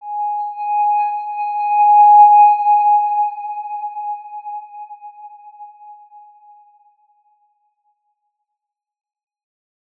X_Windwistle-G#4-mf.wav